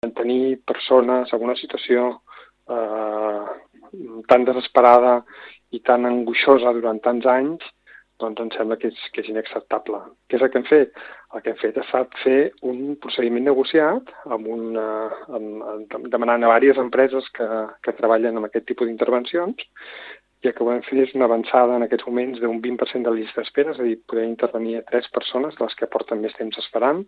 Oriol Lafau, coordinador de Salut Mental de les Illes Balears ho ha explicat a IB3 Ràdio.